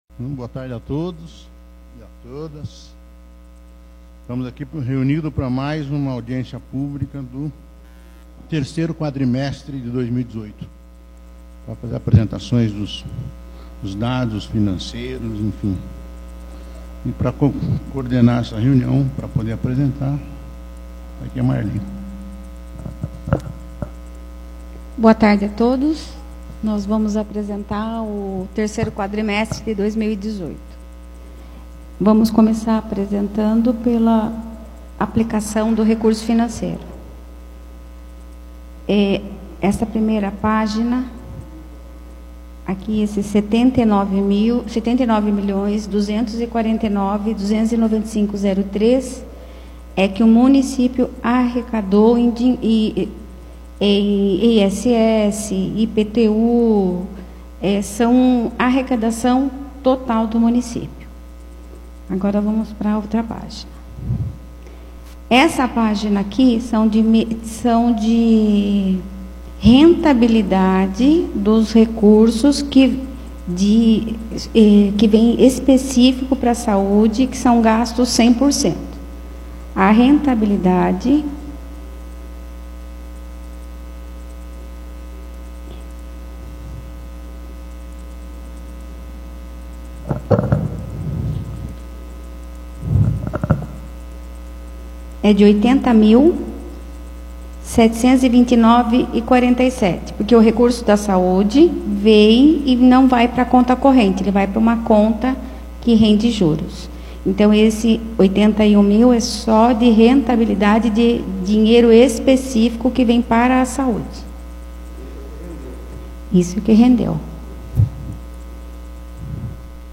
Audiência Pública da Saúde referente ao 3° quadrimestre de 2018